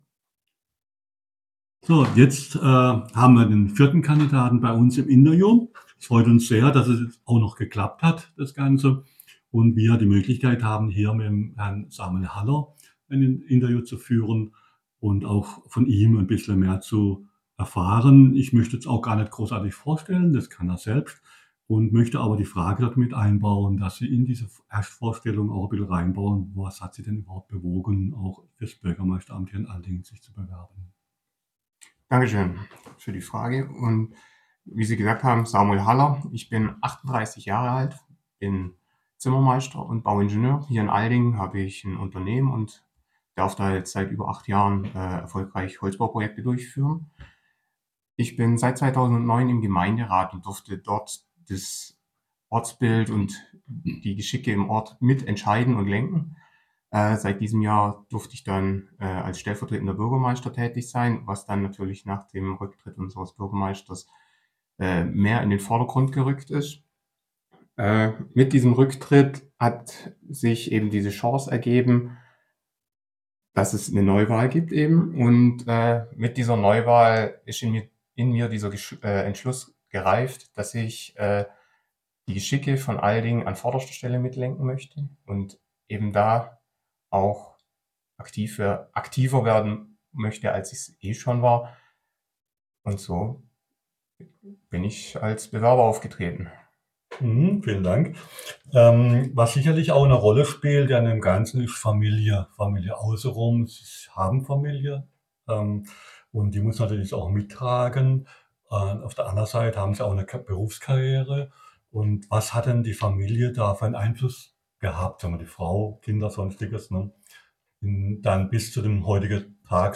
Die Wahl zum Bürgermeister in Aldingen. Ein Vorstellung der Kandidaten in einem Interview.